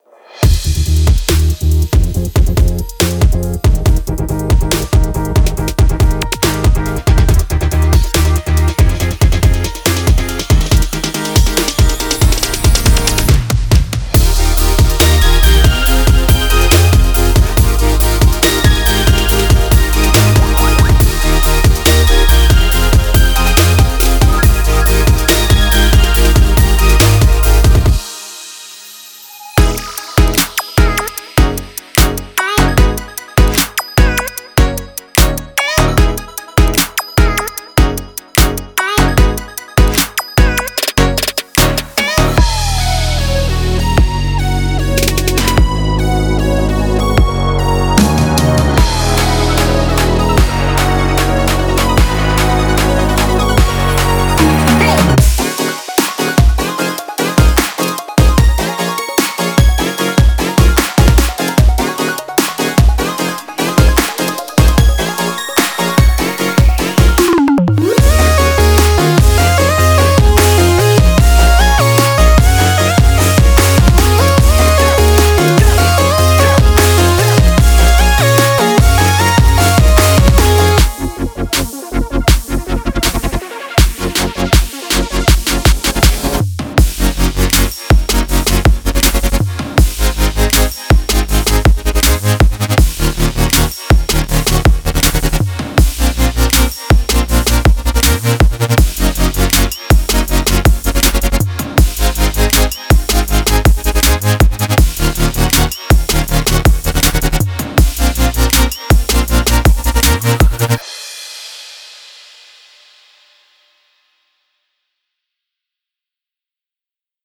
强大的Synth和弦进程，情感多样的Synthe
-35个鼓和Perc循环
.28个旋律循环